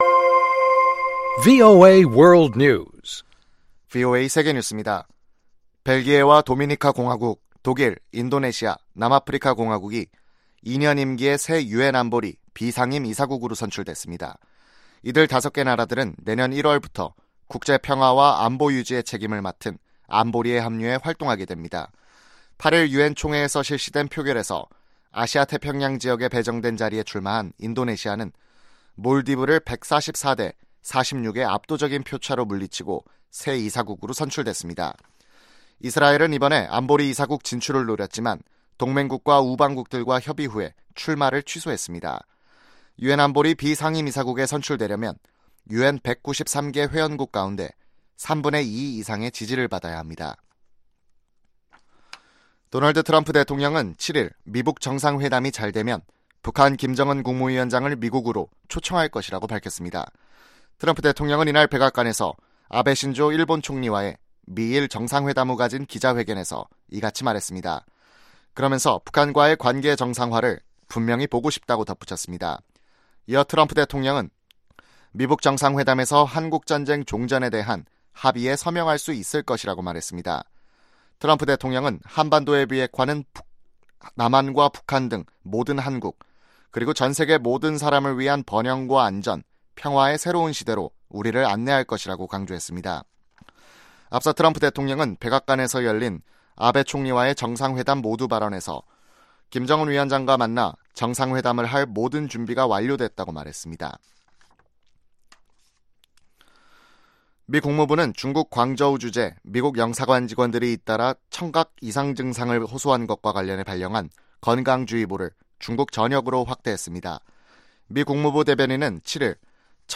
VOA 한국어 아침 뉴스 프로그램 '워싱턴 뉴스 광장' 2018년 6월 9일 방송입니다. 트럼프 대통령은 북한과의 정상회담을 앞두고 최대압박이라는 말을 안 하기로 했지만 협상이 실패할 경우 다시 그런 표현을 쓸 수 있다고 밝혔습니다. 마이크 폼페오 국무장관은 김정은 국무위원장이 비핵화할 준비가 돼 있음을 시사했다고 밝혔습니다.